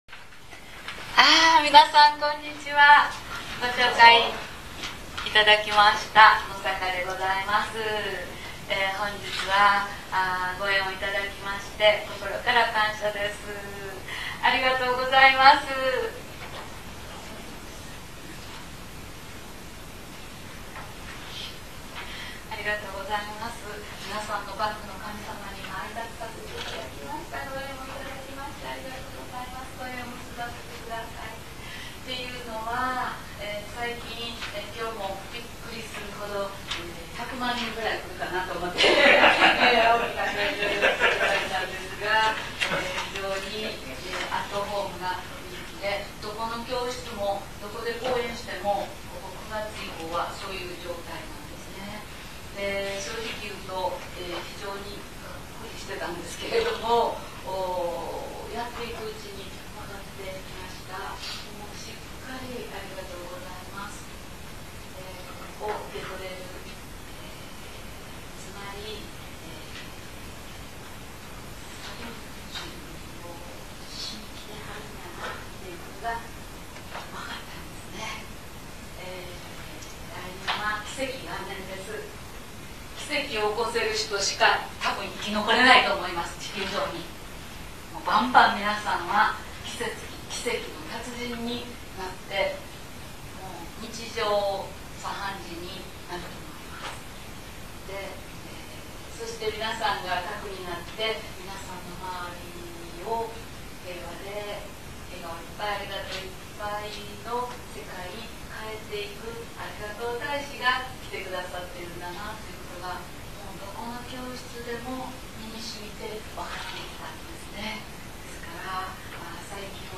定点で録音したため音声の大・小があります。
音声の一部にノイズがございます。
収録時のノイズですのでＣＤの不良品ではございませんことをご了承ください。
合計収録時間　１２６分６秒　阿倍野市民学習センターにて収録